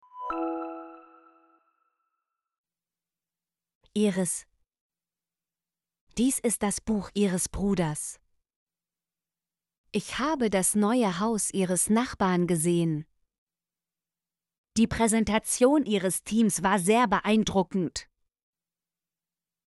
ihres - Example Sentences & Pronunciation, German Frequency List